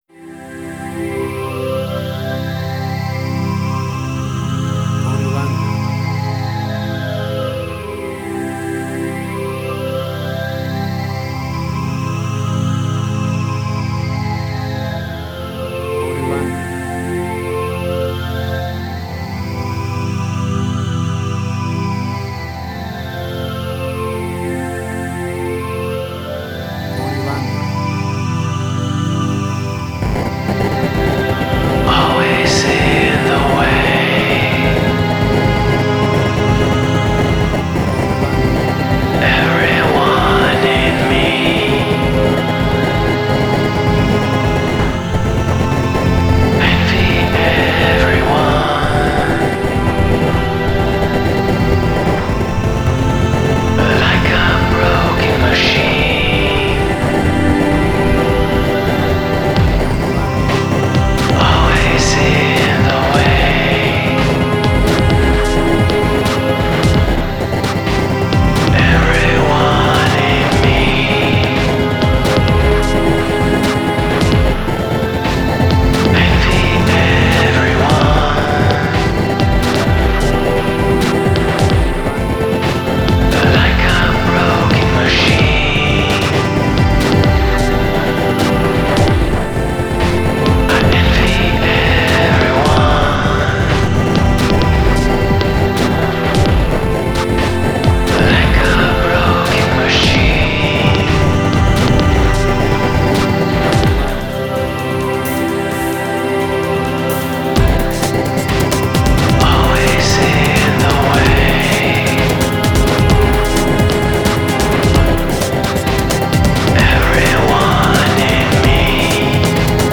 IDM, Glitch.
WAV Sample Rate: 16-Bit stereo, 44.1 kHz
Tempo (BPM): 66